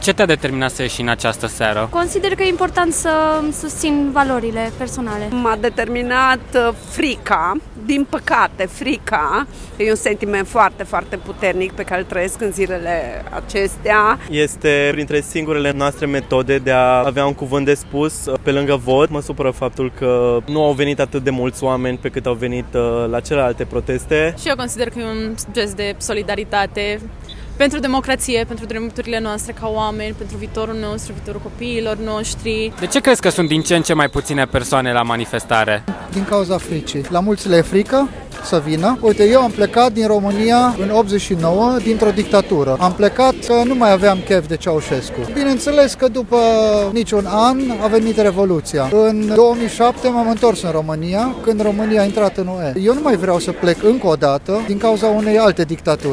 Ce au spus manifestanții:
VOX-05-12-manifestare.mp3